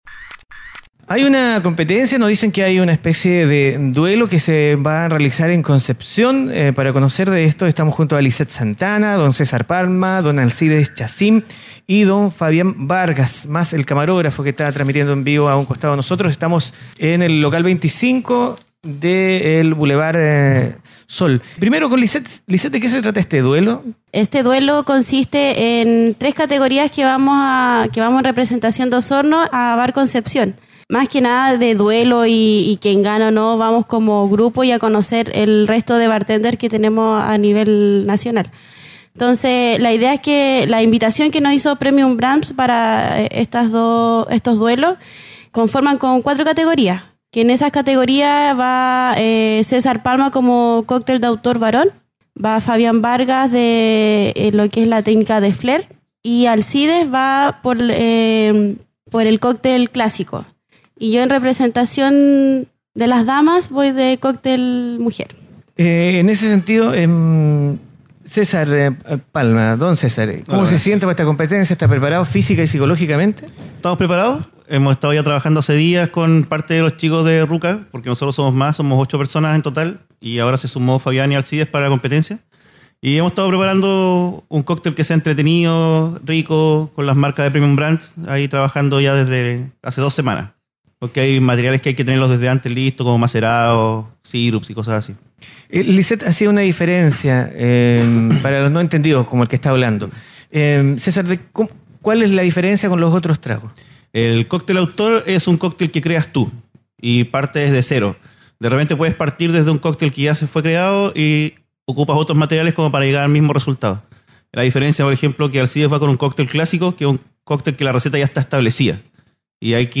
En una nueva entrevista de Paislobo Podcasts, conversamos con los representantes Osorninos que viajarán a Concepción a un Duelo de Bartenders.